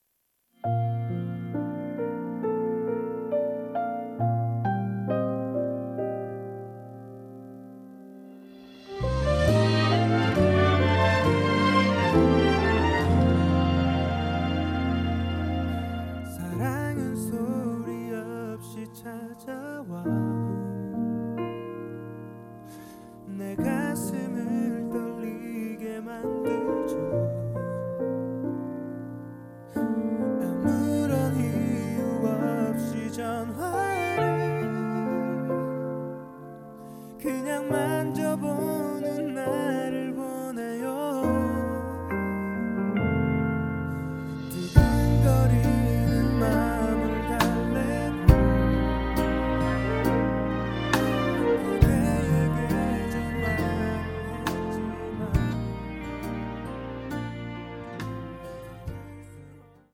음정 -1키
장르 가요 구분